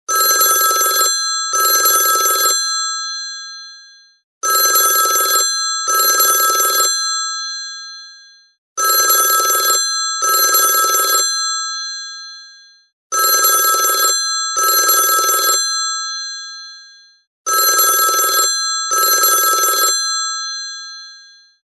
Category: Old Phone Ringtones